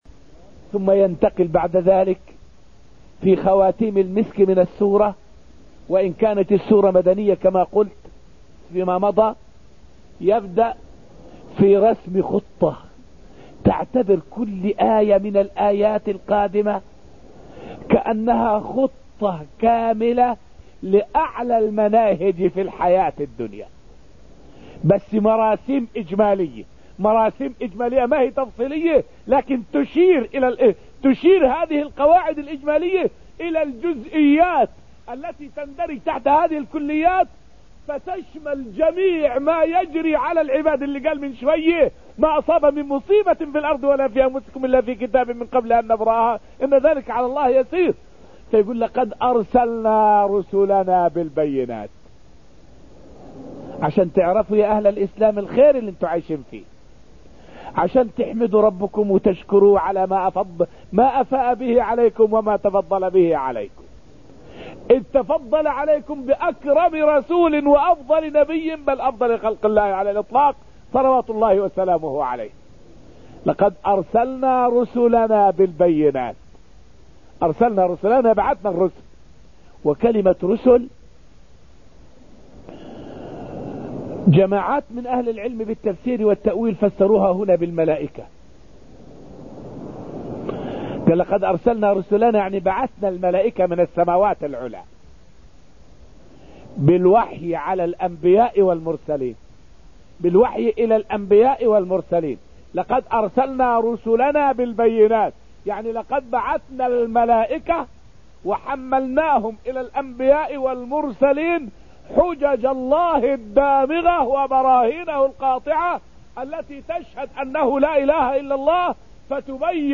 فائدة من الدرس الثاني والعشرون من دروس تفسير سورة الحديد والتي ألقيت في المسجد النبوي الشريف حول معنى قوله تعالى: {لقد أرسلنا رسلنا بالبينات}.